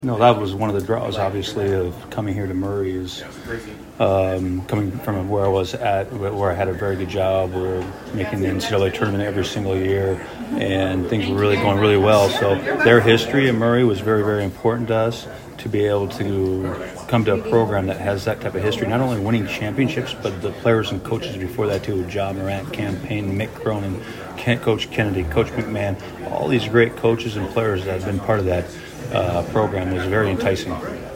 talks basketball to Hopkinsville Kiwanis Club